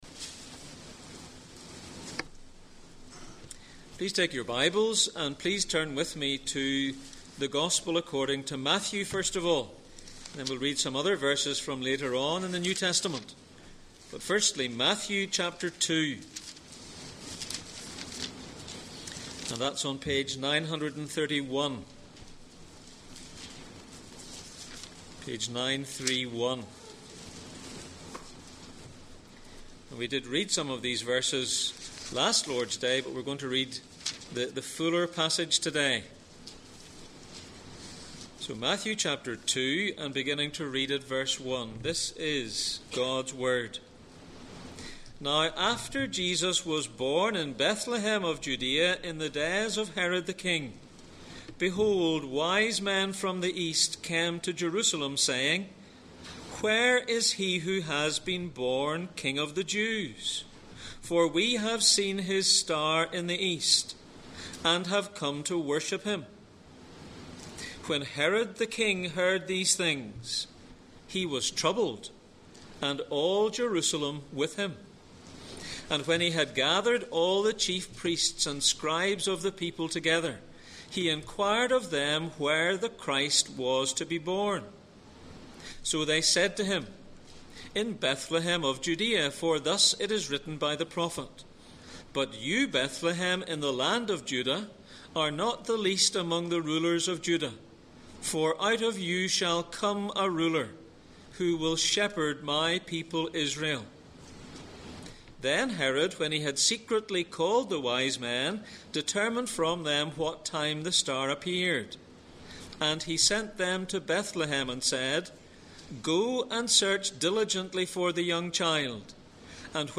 1 Corinthians 1:25-31 Service Type: Sunday Morning %todo_render% « Within a manger lies